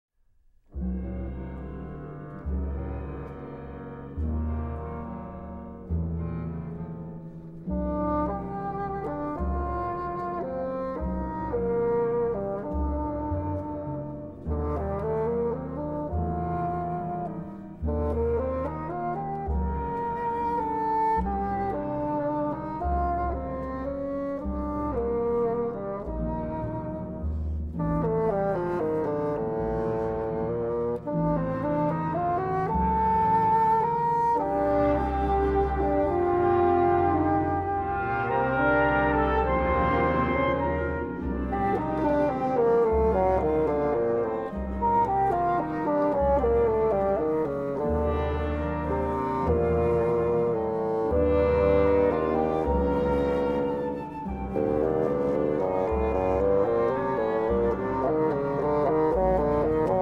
Bassoon
Tenor